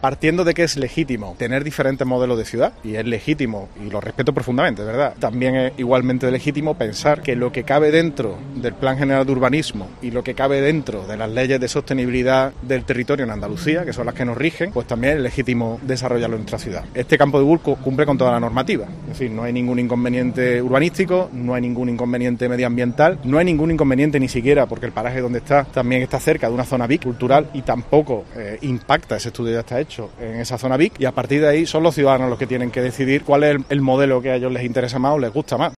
Escucha a José María Bellido sobre el nuevo campo de golf de la Avenida de Medina Azahara